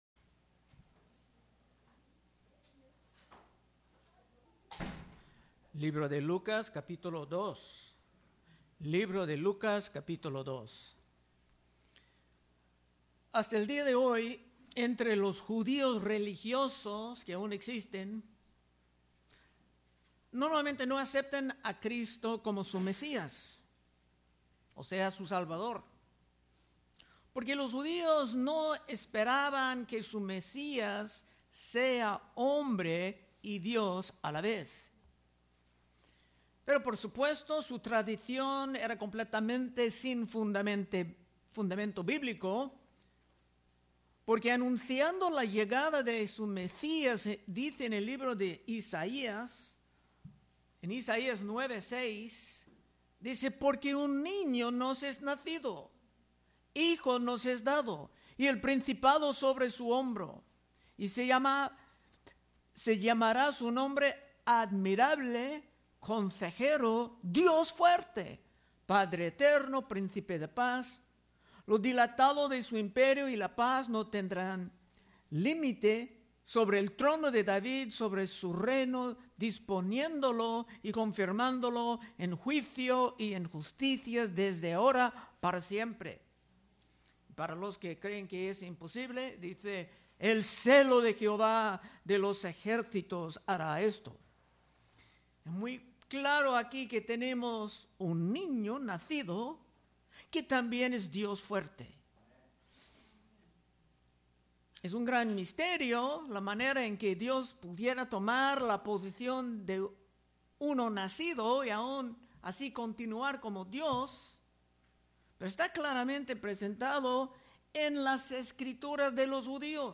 Predicaciones De Exposición Libro De Lucas